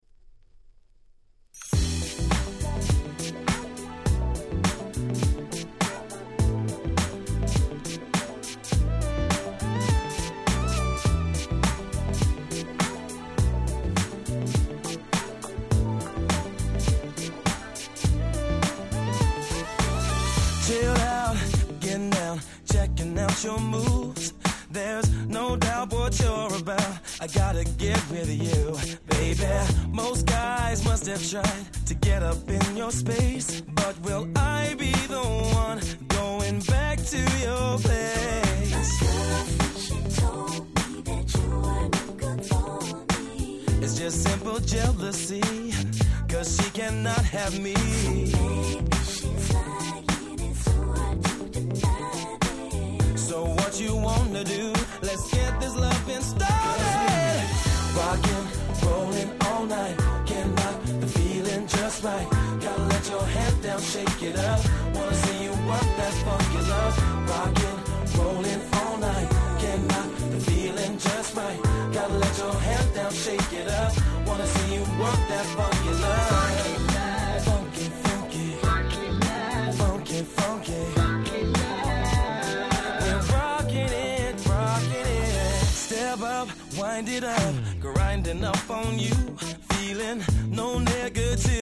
05' Super Nice UK R&B/Neo Soul !!